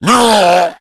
Index of /cstrike/sound/RA_Zmsounds/Pain
zombie_pain4.wav